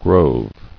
[grove]